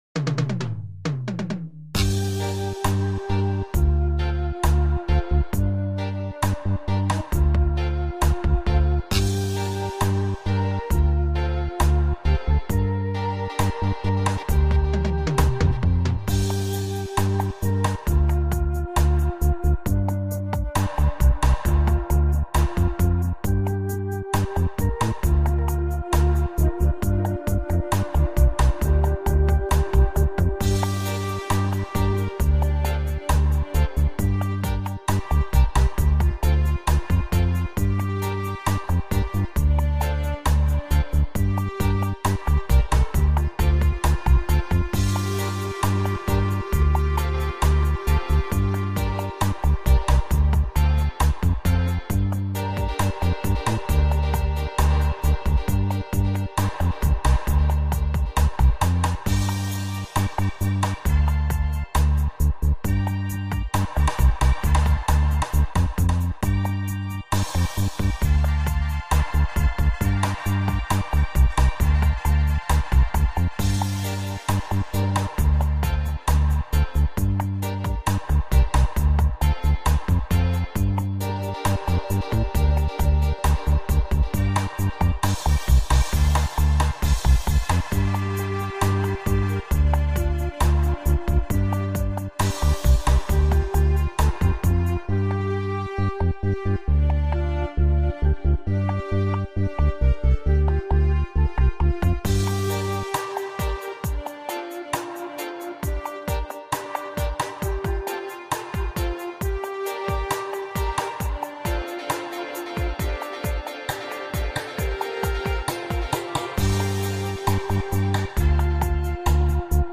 smoothbass-dub-project-x6yZJ